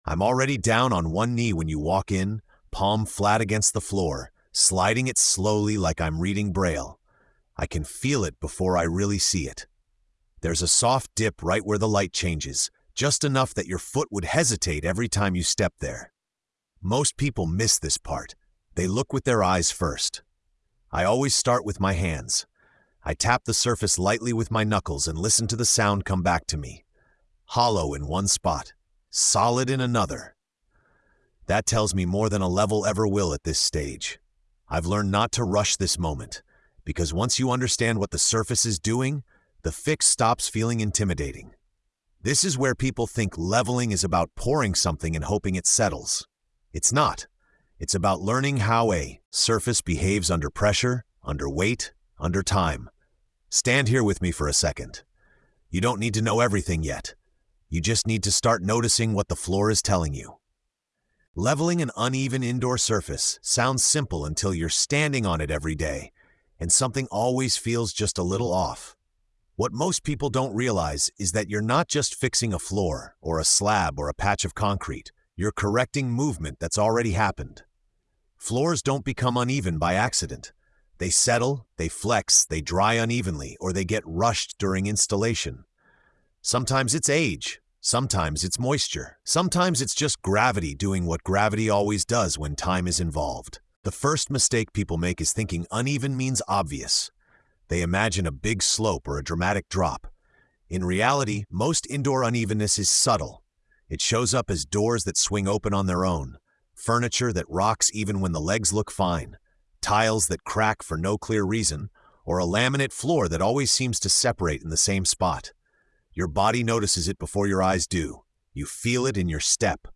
In this episode of TORQUE & TAPE, the listener is guided through the quiet but critical craft of leveling uneven indoor surfaces—a task that seems simple until lived experience proves otherwise. Told from the perspective of a seasoned tradesman working hands-on at floor level, the episode explores how unevenness is felt before it’s seen, and how patience, judgment, and restraint matter more than speed or brute force. The tone is calm, grounded, and confidence-building, emphasizing awareness, touch, and professional intuition over rigid instruction.